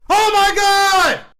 oh my god sound effects
oh-my-god